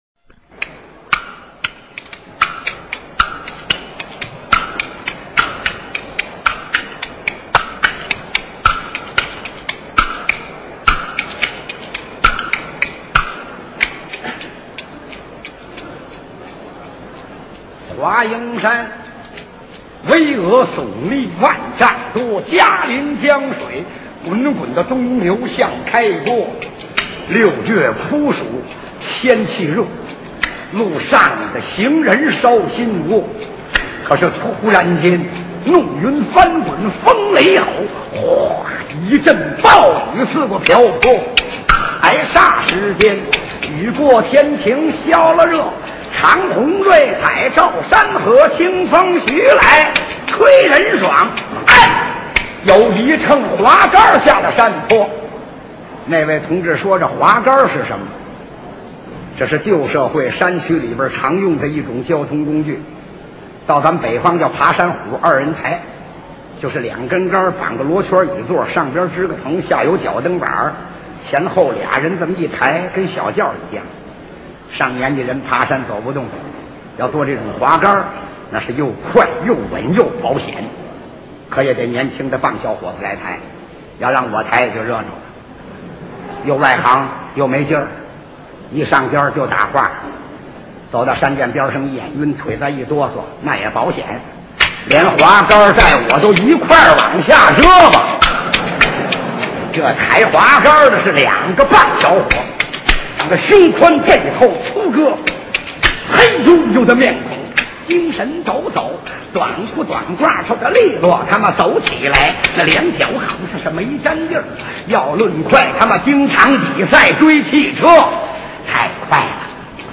[3/8/2010]劫刑车--李润杰现场完整版1962年